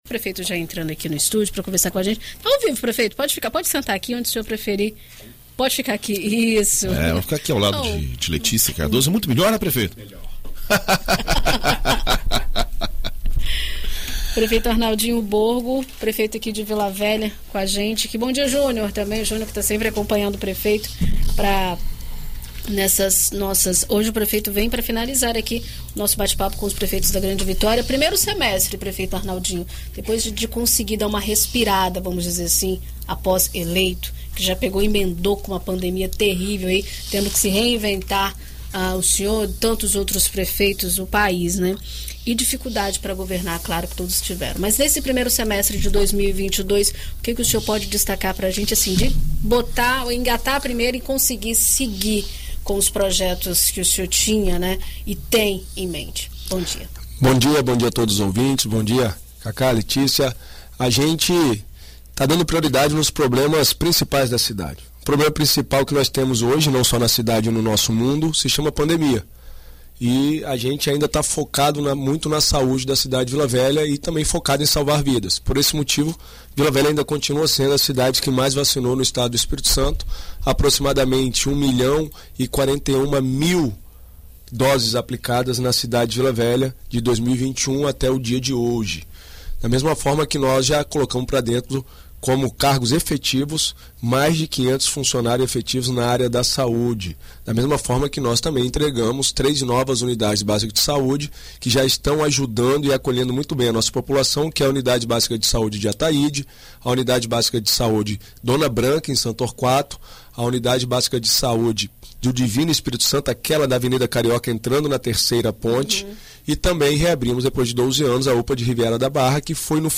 Em entrevista à BandNews FM Espírito Santo, o prefeito Arnaldinho Borgo fala sobre novos investimentos nas estações de bombeamento e a reurbanização da orla
Dando prosseguimento às entrevistas com as administrações municipais da Grande Vitória, o BandNews FM Espírito Santo 1ª Edição recebeu no estúdio o prefeito de Vila Velha, Arnaldinho Borgo.